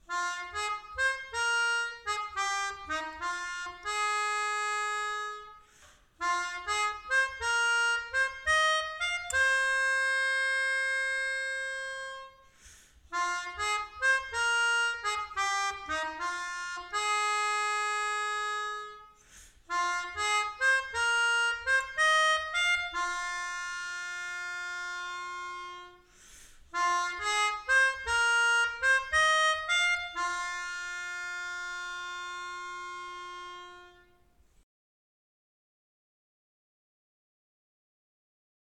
Melodica / Pianica / Airboard